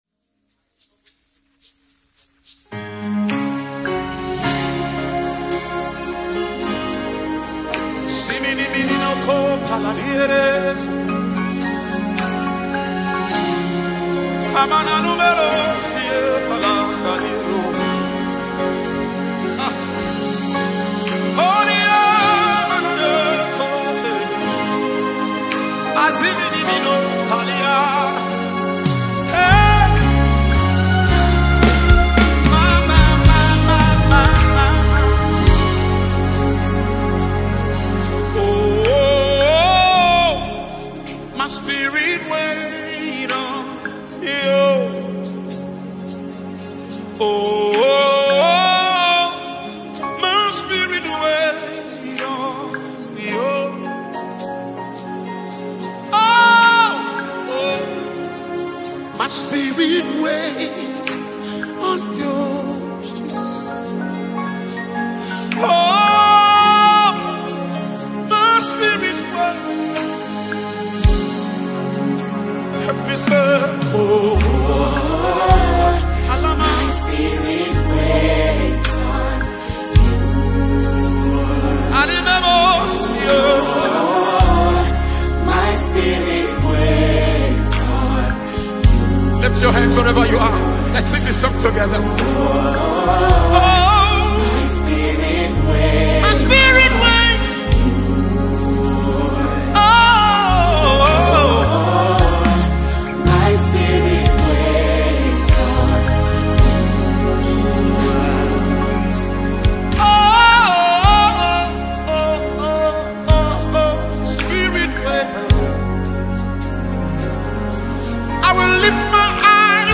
With his soothing vocals and heartfelt lyrics
soul-stirring melodies
heartfelt worship